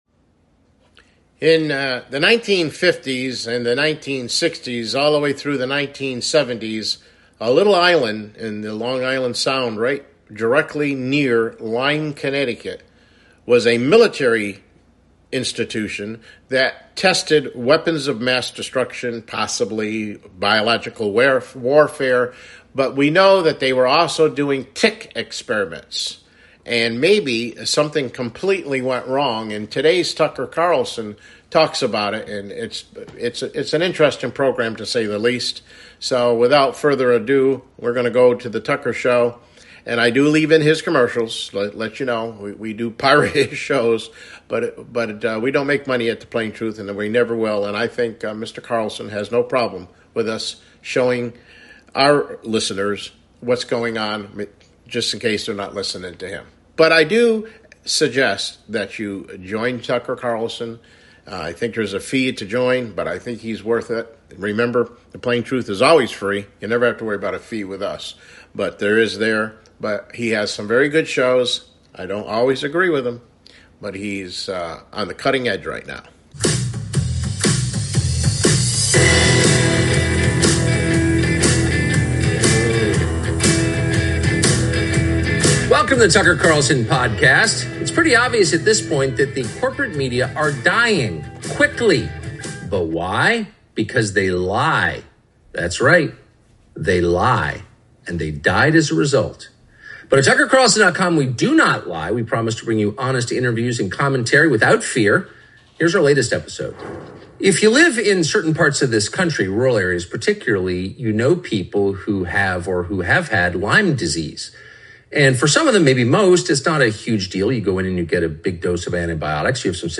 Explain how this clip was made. CLICK HERE TO LISTEN TO THE PLAIN TRUTH TODAY MIDDAY BROADCAST: Lyme Disease | Man Made?